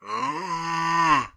sounds_undead_moan_04.ogg